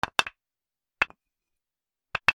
/ M｜他分類 / L01 ｜小道具 /
木を机に叩きつける 木同士をぶつける 『カタ』